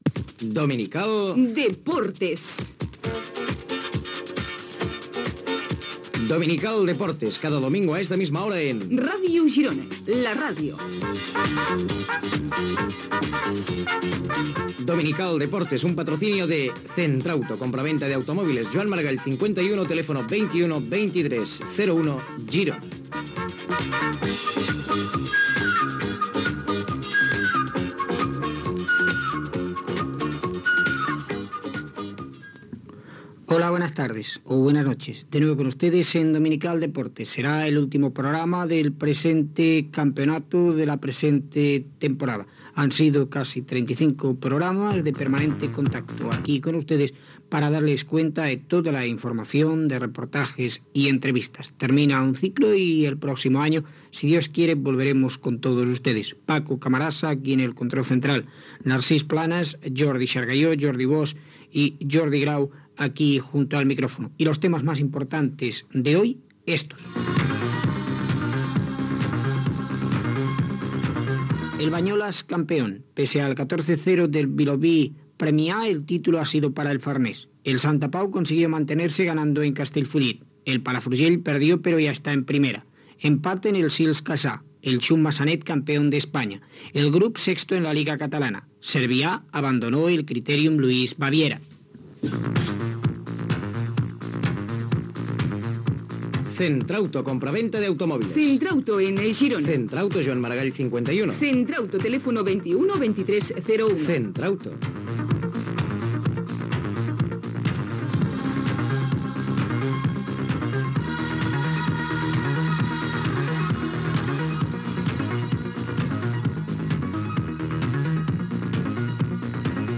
Publicitat, careta del programa, sumari informatiu, publicitat, resultats poliesportius d'equips gironins
Esportiu